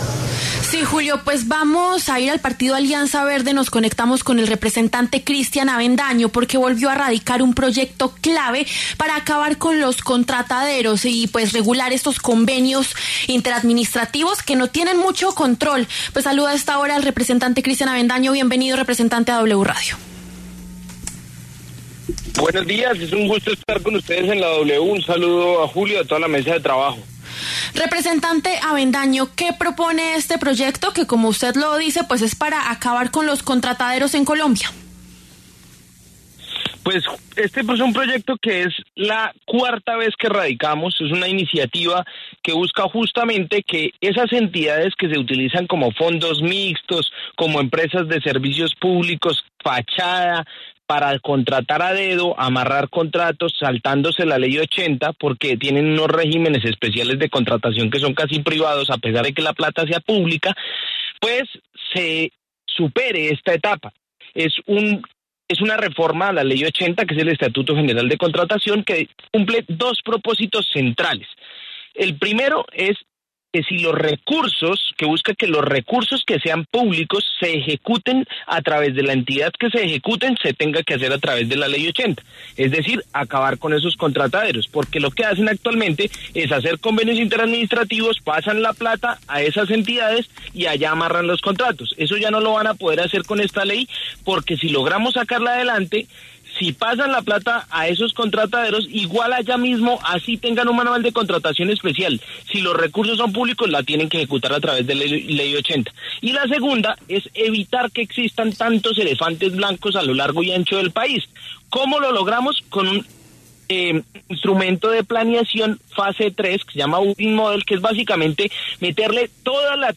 El representante a la Cámara, Cristian Avendaño, conversó con La W sobre su proyecto de ley para acabar los “contrataderos”.